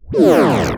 SCIFI_Sweep_01_mono.wav